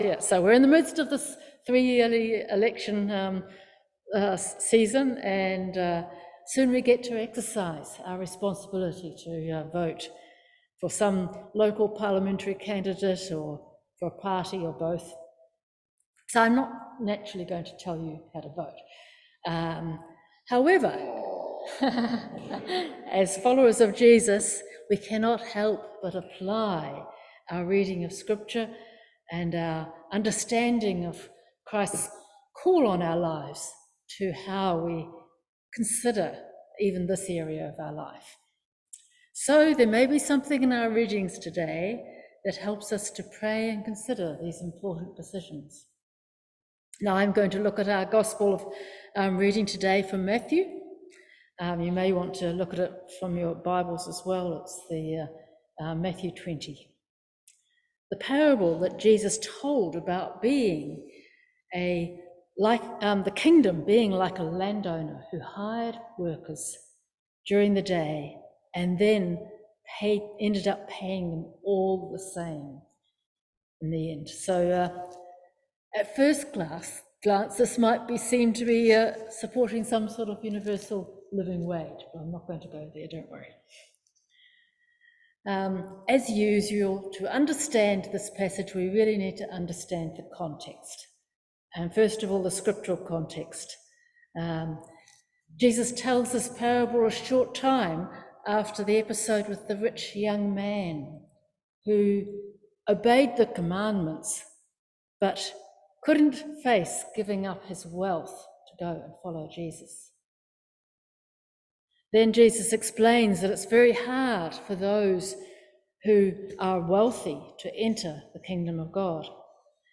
Matthew 20:1-16 Service Type: Morning Worship Election year and the gift of God's grace.